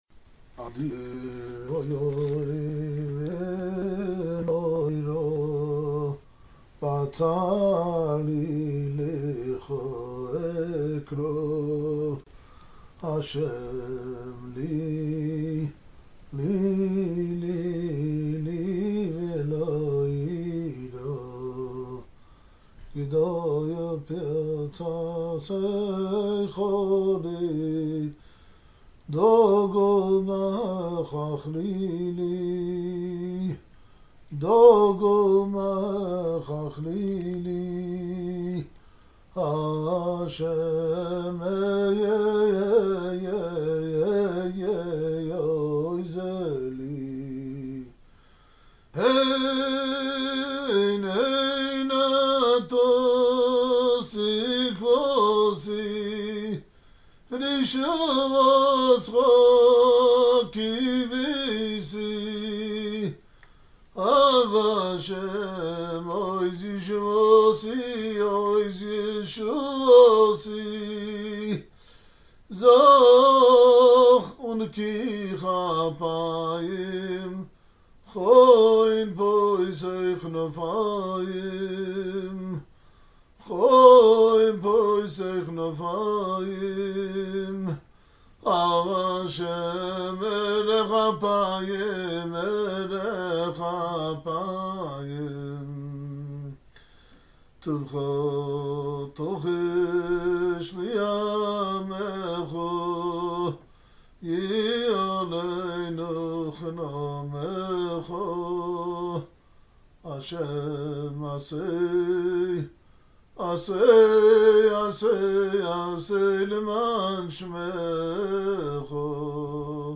shared this tremendous d'veikes nigun at a Melave Malka with the Happy Minyan at the Breslov shul in Los Angeles during a speaking tour.